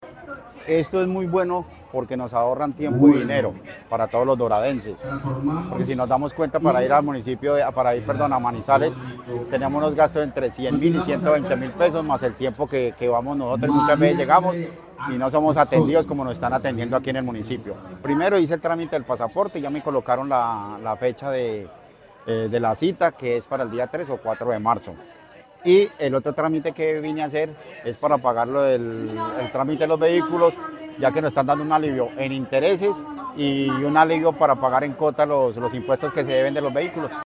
habitante de La Dorada.